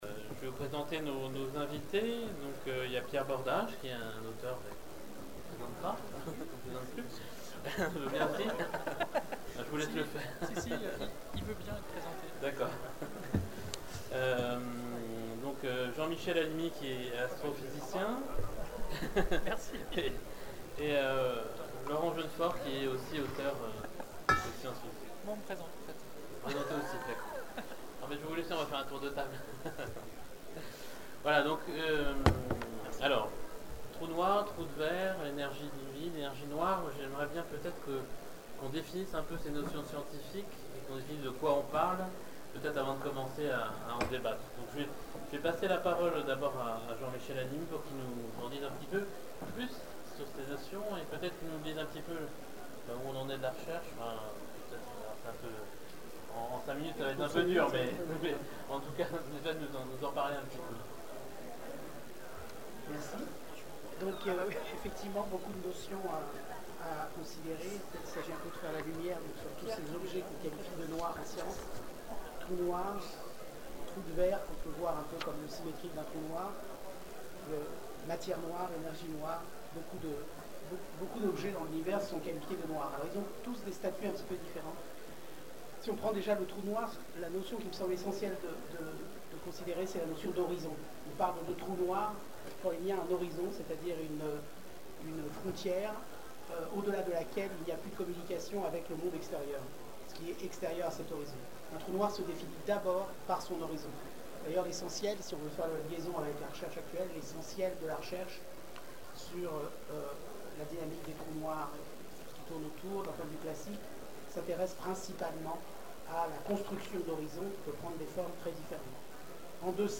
Zone Franche 2012 : Conférence Trou noir, trou de ver et énergie du vide
(Attention, le son n'est pas très bon) Télécharger le MP3